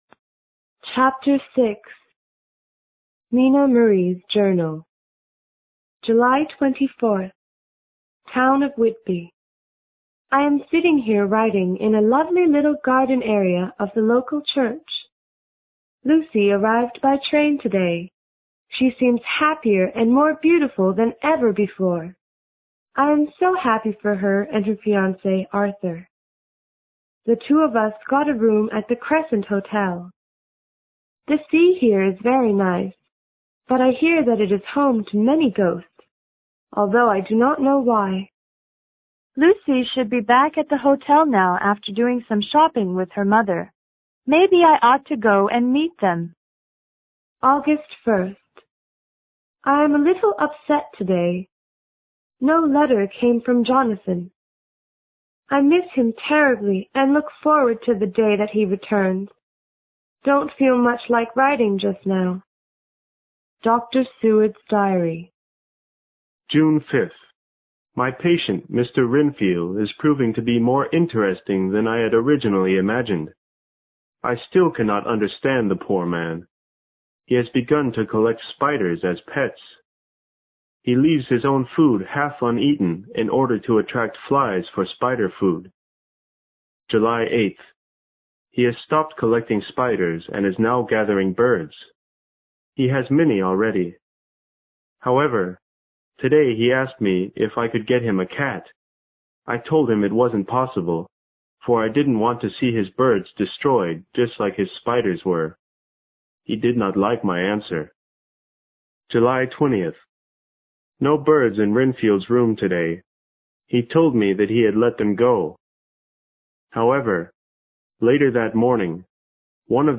有声名著之吸血鬼 Chapter6 听力文件下载—在线英语听力室